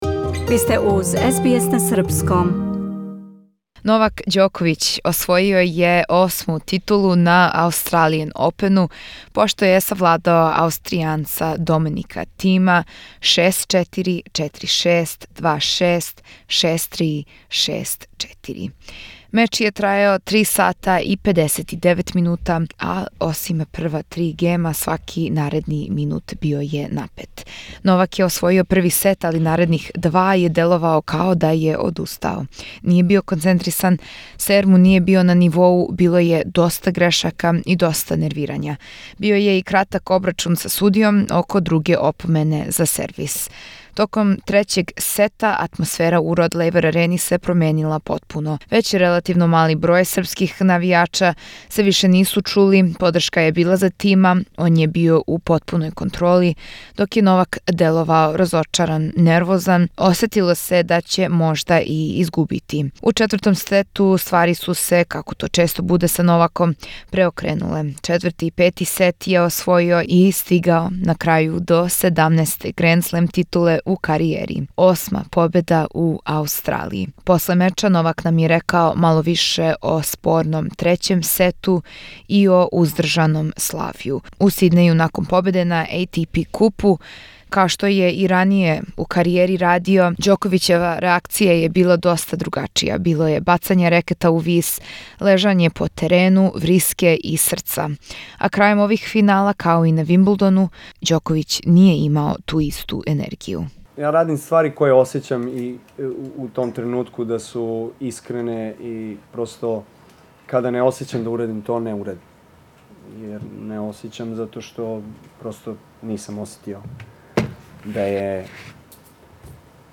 Разговор са Ђоковићем након осме победе у Аустралији
Новак Ђоковић победио је Доминика Тима и освојио осму титулу на Аустралиан Опену. СБС на Српском доноси Вам разговор са српским тенисером одмах након меча.